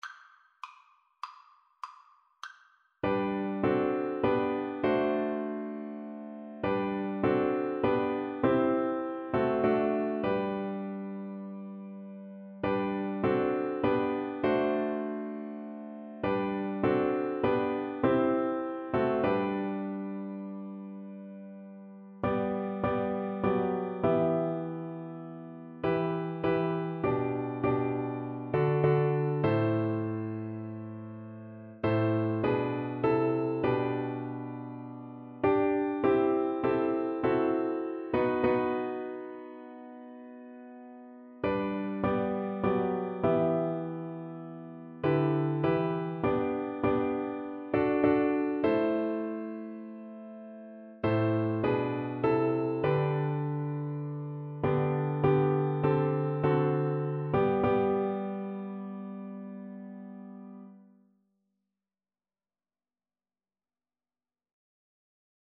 Christian
4/4 (View more 4/4 Music)
Traditional (View more Traditional Violin Music)